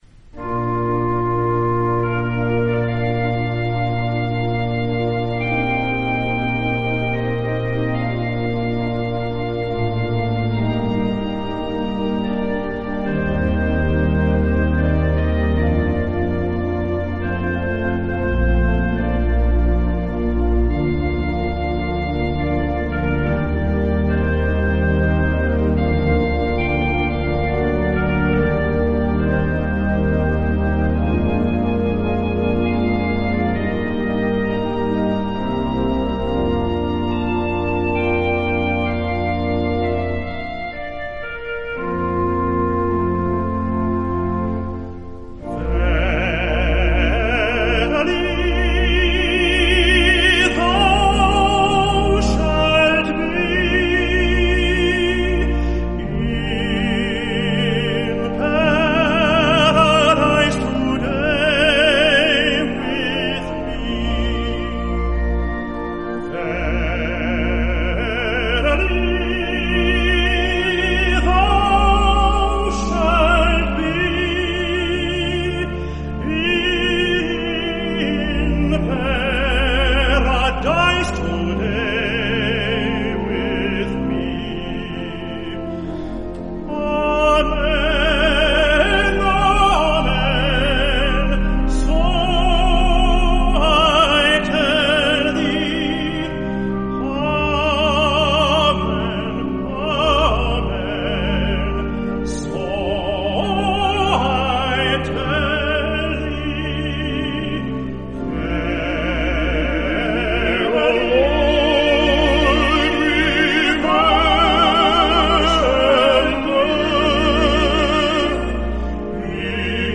“The Second Word—Verily Thou Shalt Be in Paradise Today with Me” from The Seven Last Words of Christ. Composed by Théodore Dubois. Performed by Choir of the First United Methodist Church